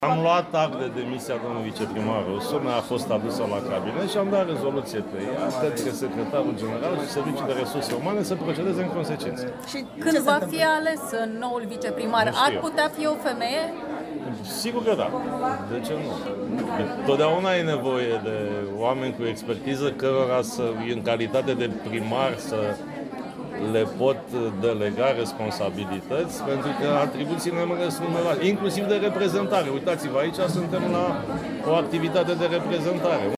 Prezent, vineri, la deschiderea Conferința Internațională a femeilor primar, edilul Vergil Chițac a spus că a luat act de demisia viceprimarului Ionuț Rusu și că nu exclude ca funcția lăsată liberă de colegul său de partid să fie ocupată de o femeie.